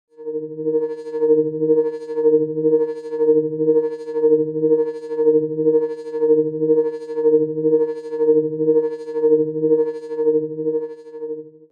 دانلود آهنگ موج 5 از افکت صوتی طبیعت و محیط
جلوه های صوتی
دانلود صدای موج 5 از ساعد نیوز با لینک مستقیم و کیفیت بالا